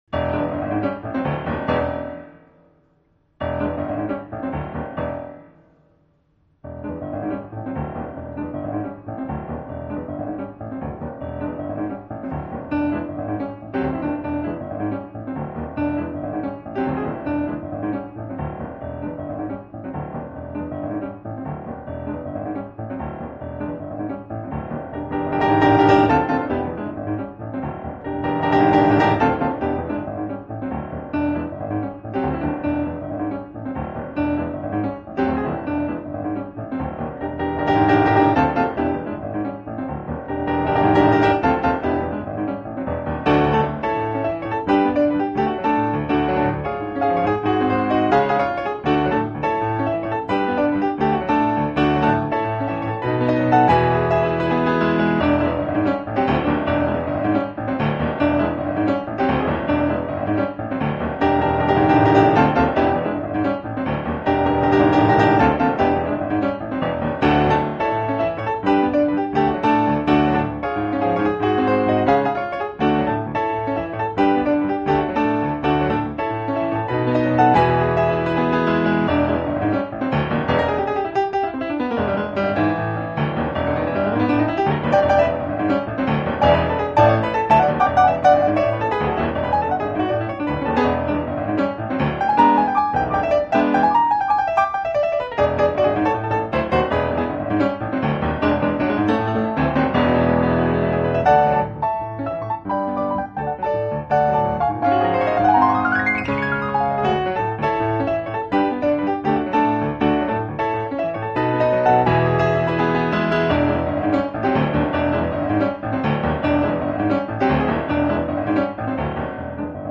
音乐风格：Jazz
本专辑为现场演奏会专辑